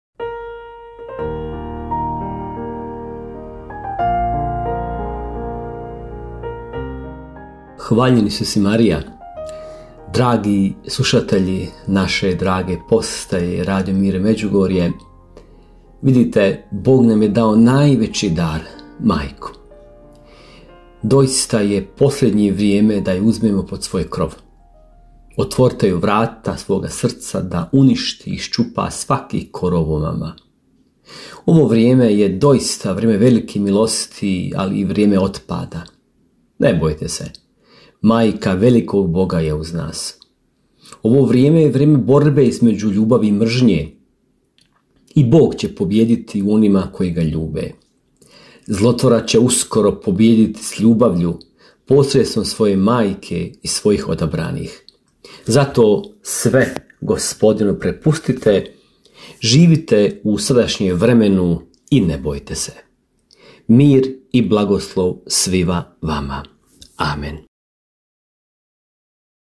Kratku emisiju ‘Duhovni poticaj – Živo vrelo’ slušatelji Radiopostaje Mir Međugorje mogu čuti od ponedjeljka do subote u 3 sata, te u 7:10. Emisije priređuju svećenici i časne sestre u tjednim ciklusima.